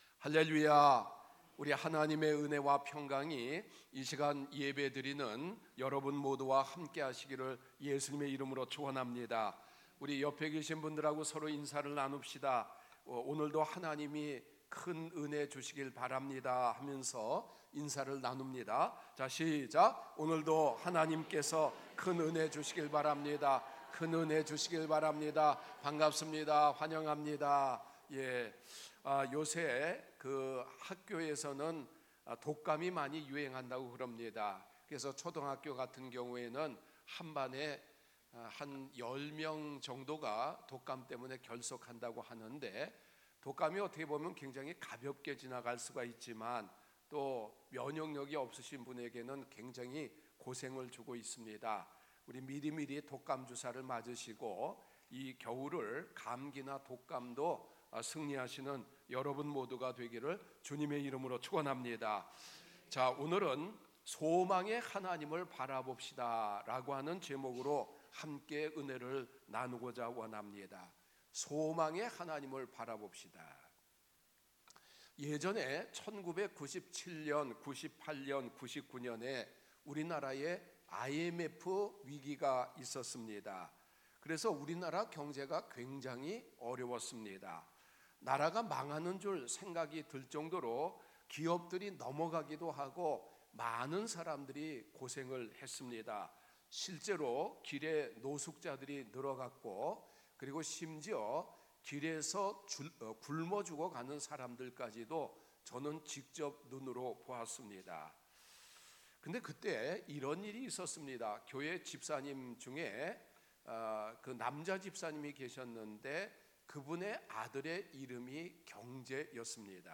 목록 share 주일설교 의 다른 글